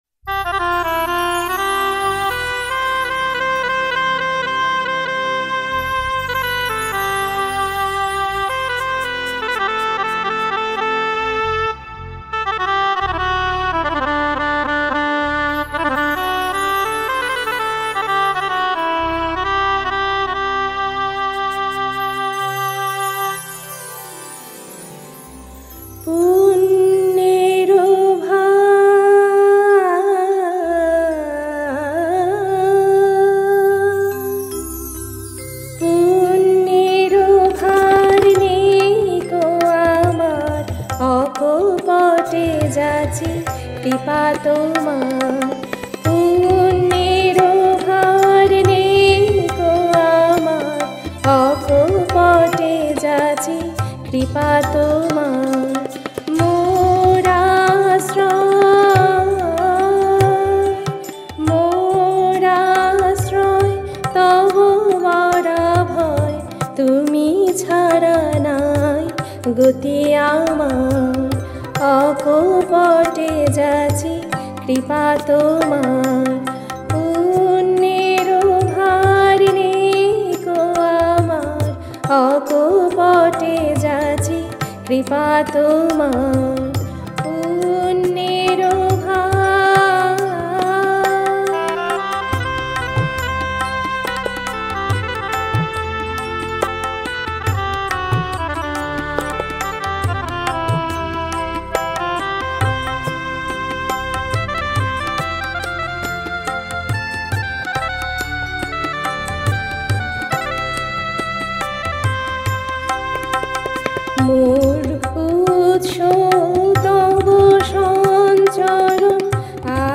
Music Dadra